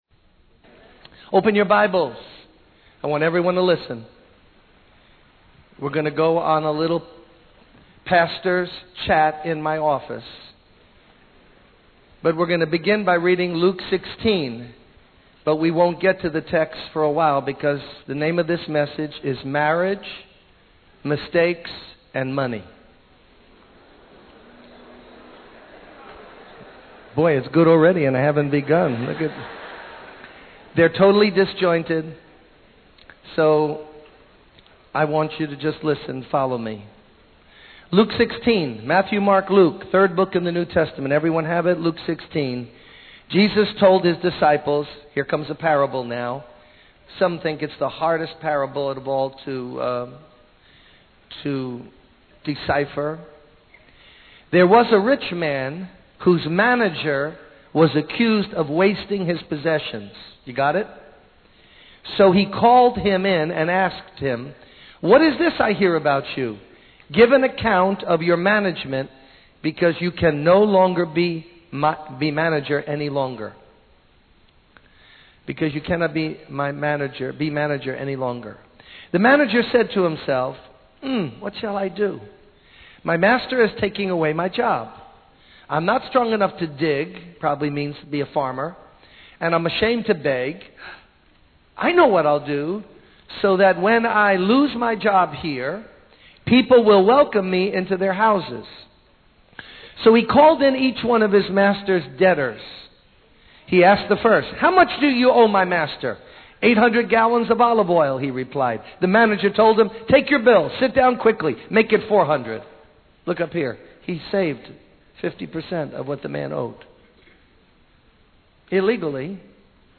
In this sermon, the preacher discusses the parable of the dishonest manager from Luke 16:1-13.